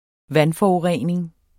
Udtale [ ˈvanfɒuˌʁεˀneŋ ]